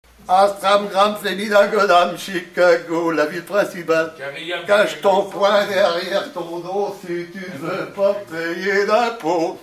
Chavagnes-en-Paillers
enfantine : lettrée d'école
Pièce musicale inédite